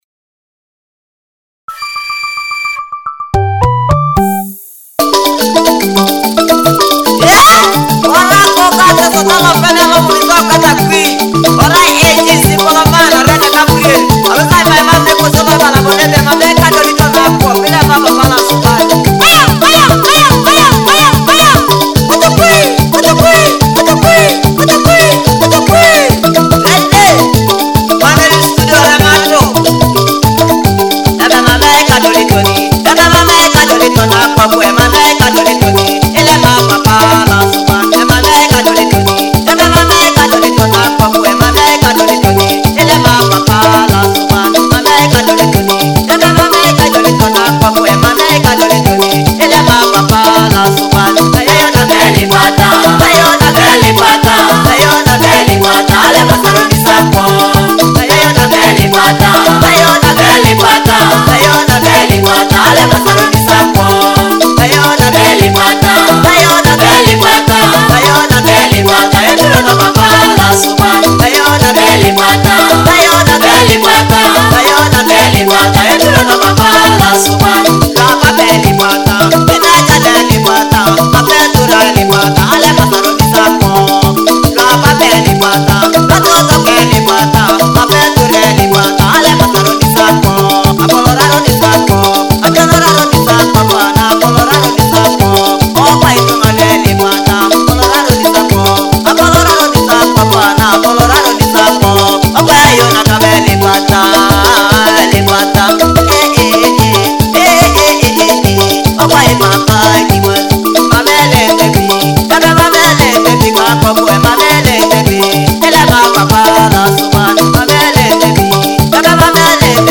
featuring joyful Ateso cultural and traditional rhythms